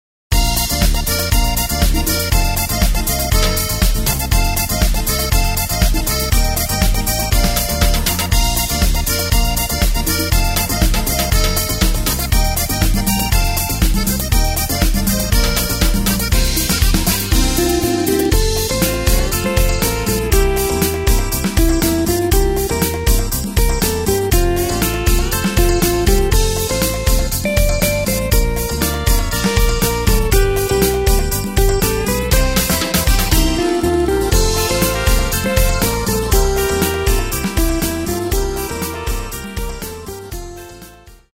Takt:          4/4
Tempo:         120.00
Tonart:            Bb
Party-Schlager aus dem Jahr 2021!